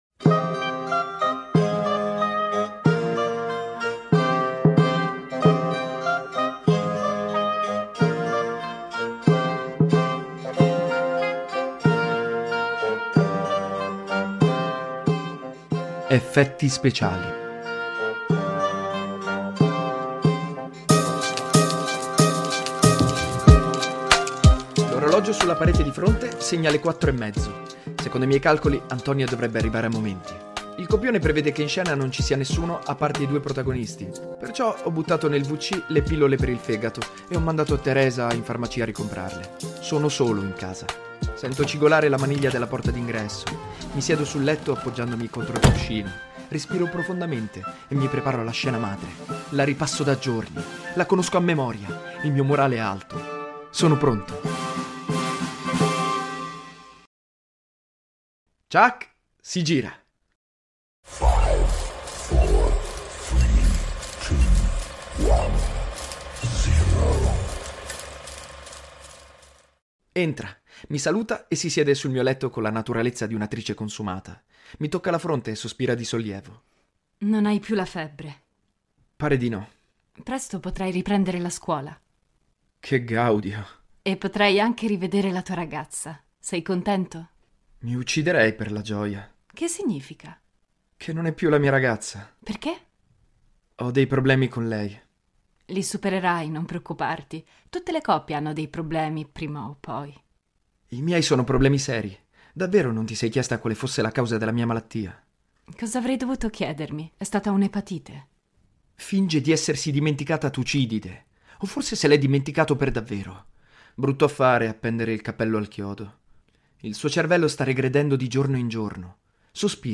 Un altro episodio cruciale del romanzo-podcast.